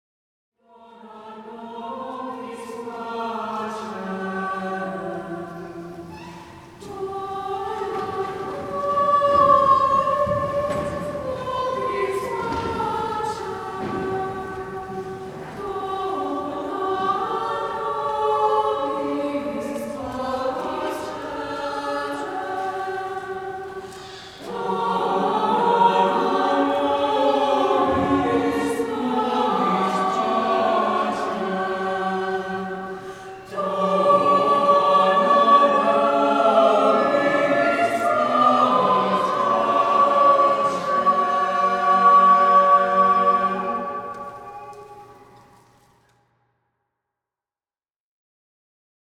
It’s hard to banish that round once it enters your ears!
Nonetheless, because this setting by Ravenscroft is so simple we were able to “put it together” by running through it just once. You can listen to this live recording (Mp3) from yesterday. I don’t claim it’s perfect, but I was extremely pleased to hear it ‘click’ together.